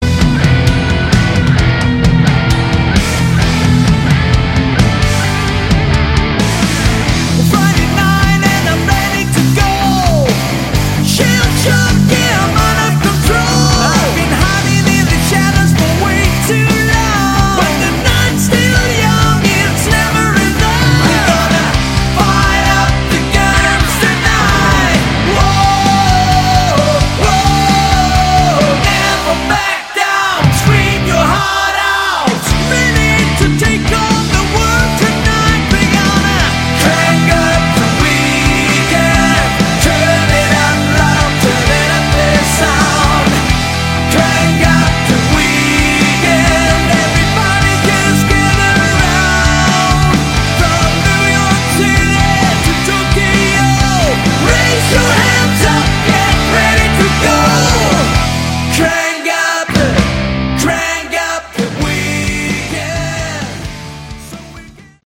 Category: AOR
guitars, keyboards
lead, rhythm, and bass guitar, backing vocals
lead and backing vocals
drums, backing vocals